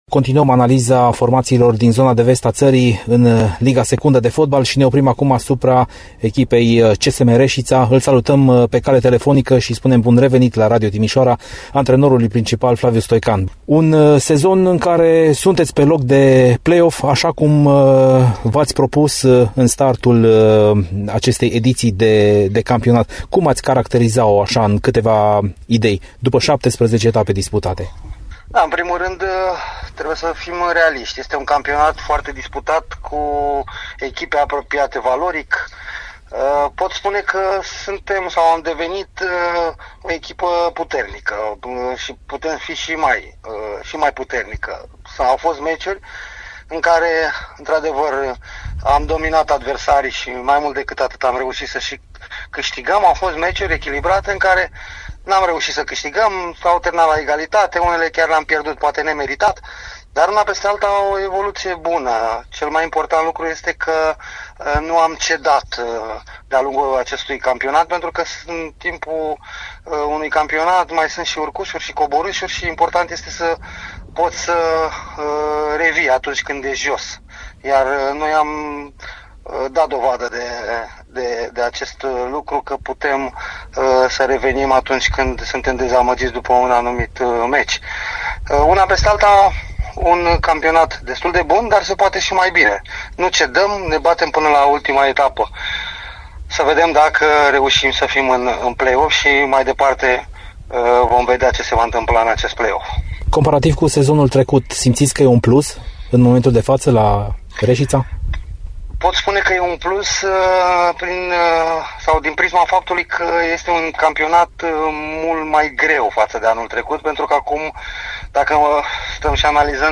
Antrenorul Flavius Stoican a analizat, la Radio Timișoara, parcursul din actuala stagiune a echipei sale.